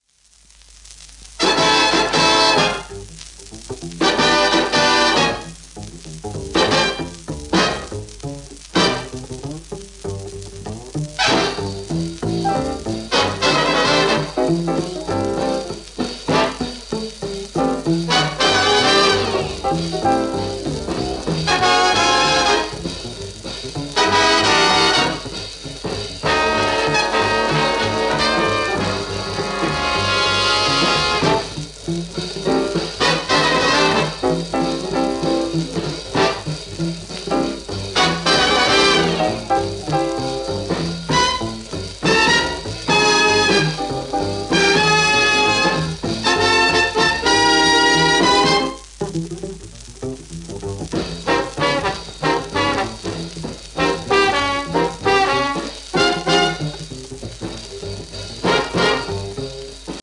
蓄音器では大音量になりますので、電気再生向きだと思います